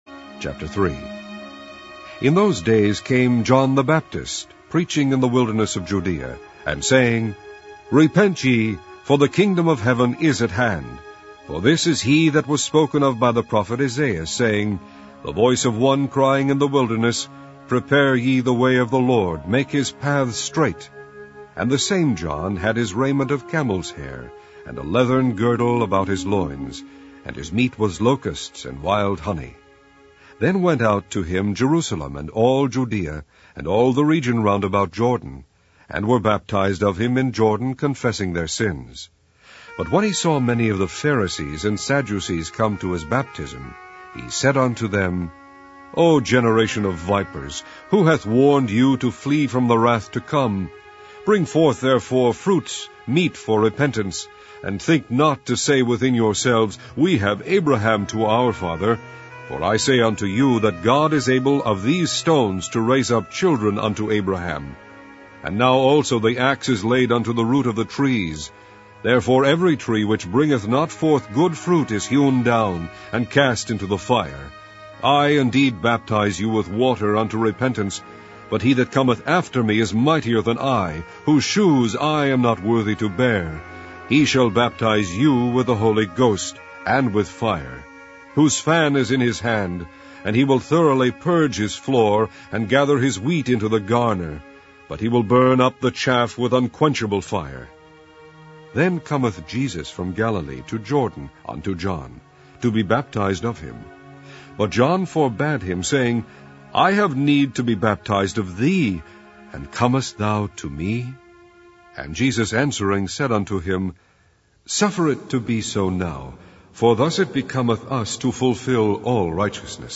Online Audio Bible - King James Version - Matthew